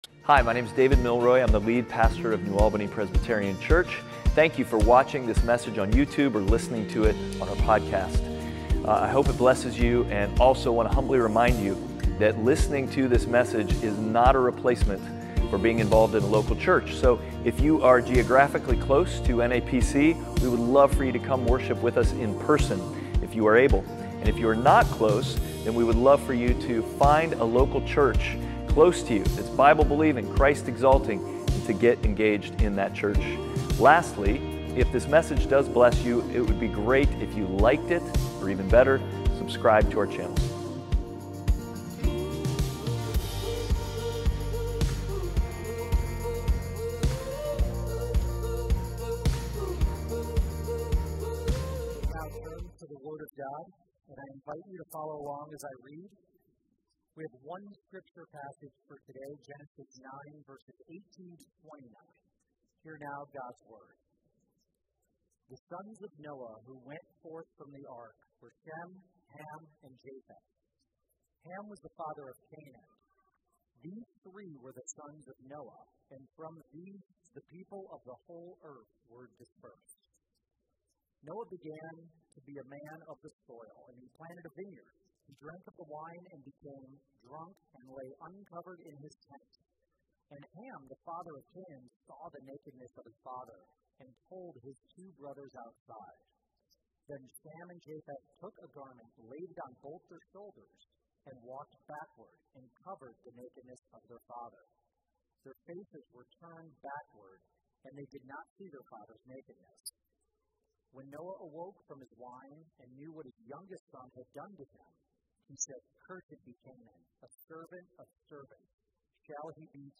Passage: Genesis 9:18-29 Service Type: Sunday Worship